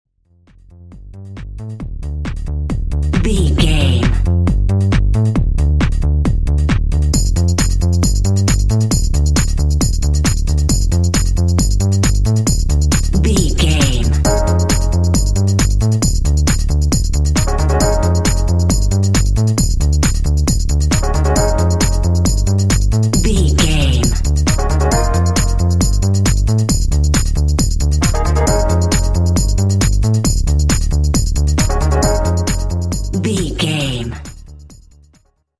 Aeolian/Minor
D
groovy
futuristic
industrial
synthesiser
drum machine
house
techno
electro house
synth lead
synth bass